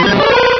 pokeemerald / sound / direct_sound_samples / cries / larvitar.aif
-Replaced the Gen. 1 to 3 cries with BW2 rips.
larvitar.aif